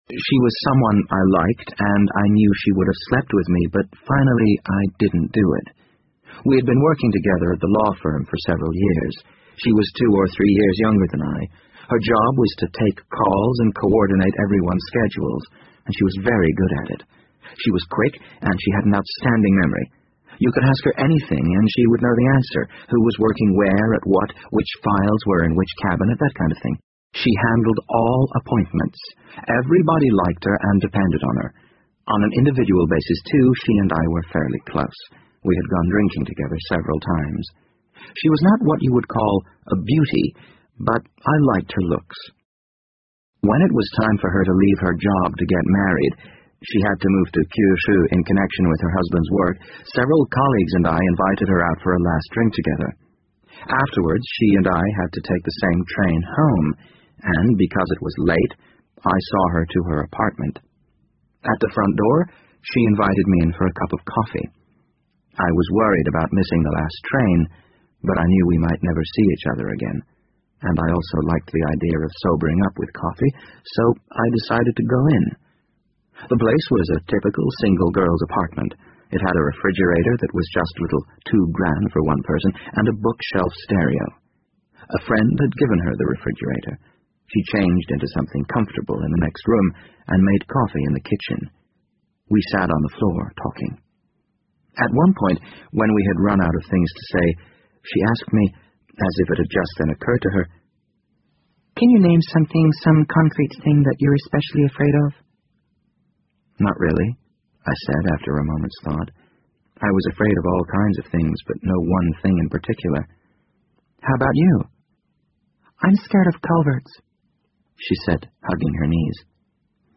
BBC英文广播剧在线听 The Wind Up Bird 52 听力文件下载—在线英语听力室